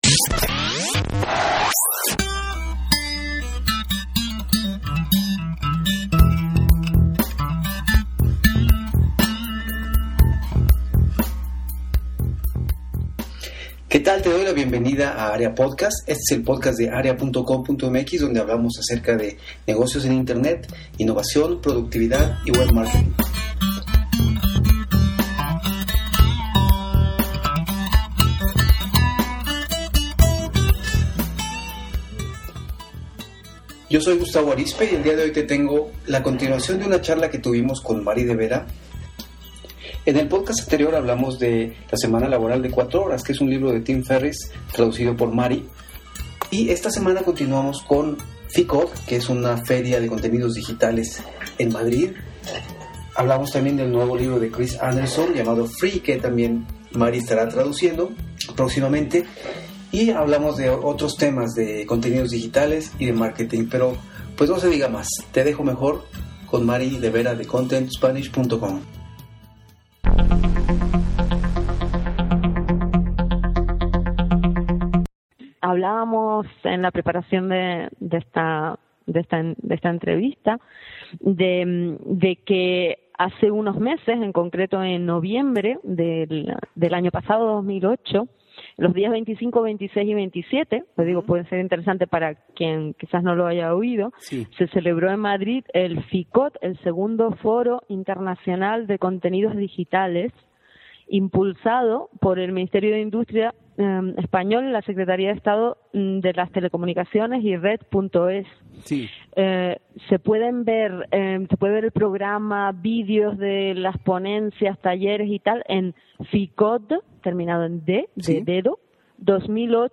Area Podcast 12 • Charla